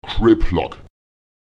Lautsprecher keplak [Èk¨Eplak] das Eigentum, der Besitz (etwas, das besessen wird)